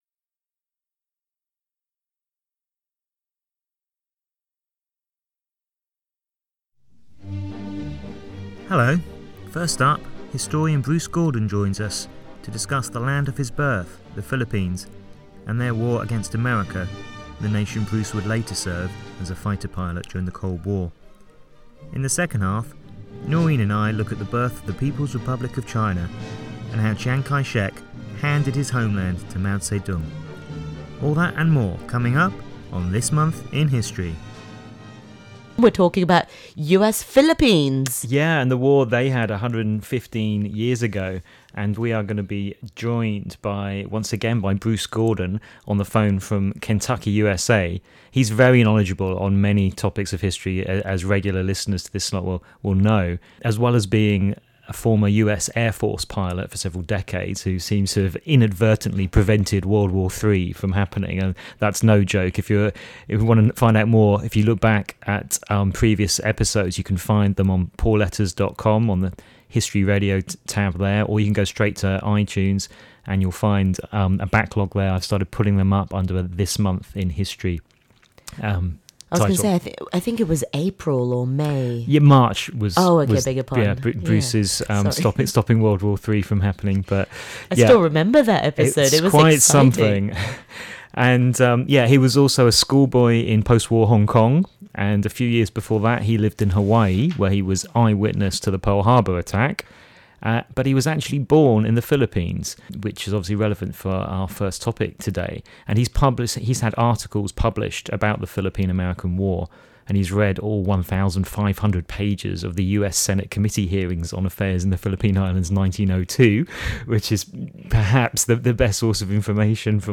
Recorded on RTHK Radio 3’s 1-2-3 Show, at Radio Television Hong Kong Studios, Broadcast Drive, Kowloon, Hong Kong.